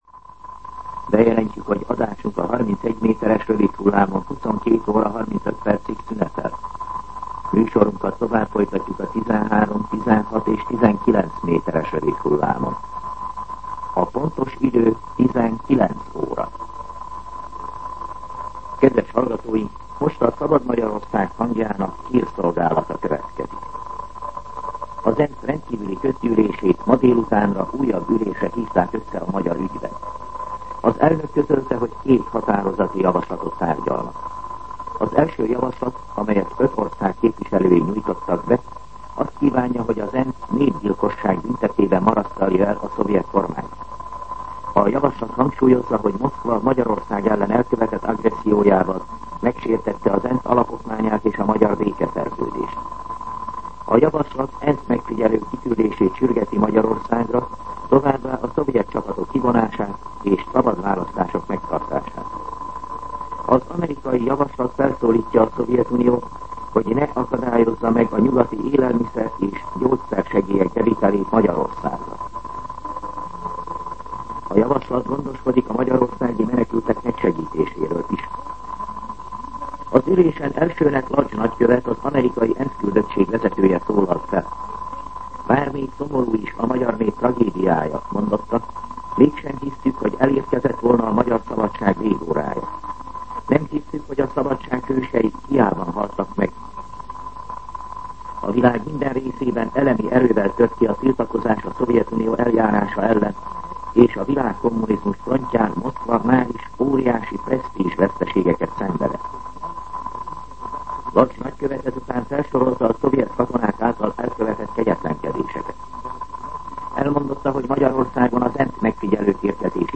19:00 óra. Hírszolgálat